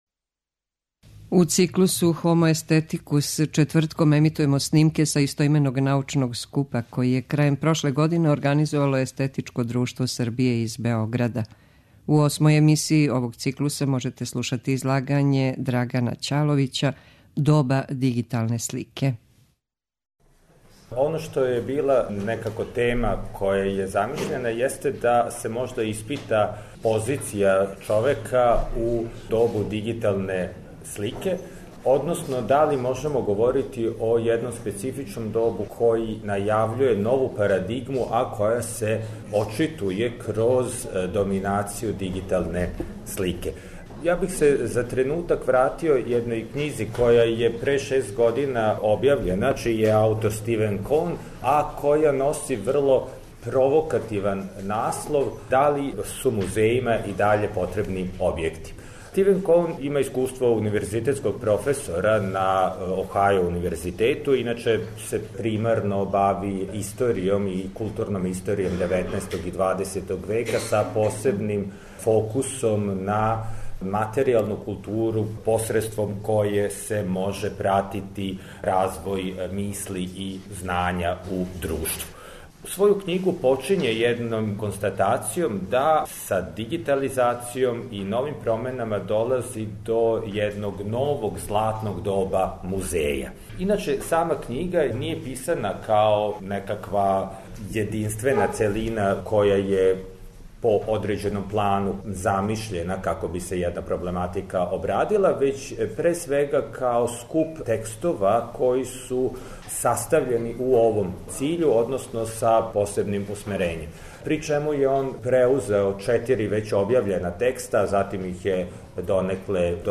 У циклусу HOMO AESTHETICUS четвртком ћемо емитовати снимке са истоименог научног скупа који је, у организацији Естетичког друштва Србије, одржан 22. и 23. децембра у Заводу за проучавање културног развитка у Београду.
Научни скупoви